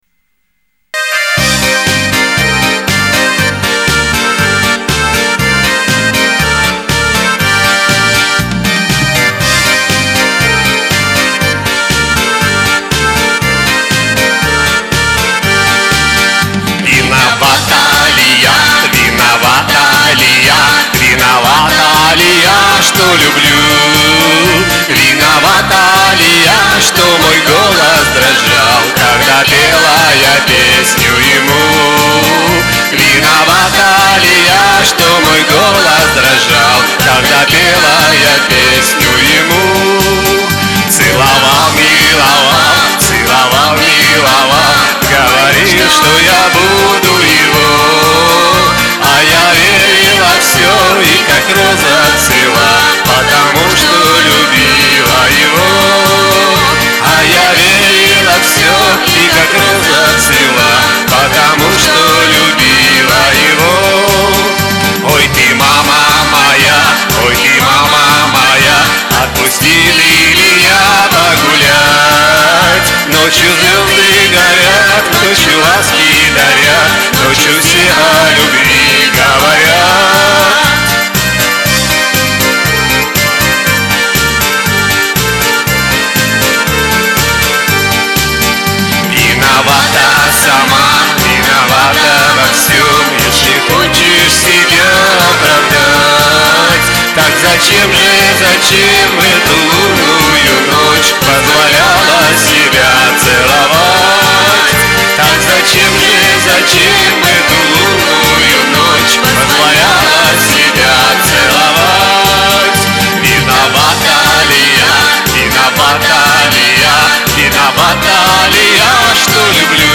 ЕСЛИ ВООБЩЕ СЛУШАЛ ПОЕТ ЖЕНЩИНА , Я  ЕЙ ПОДПЕВАЮ.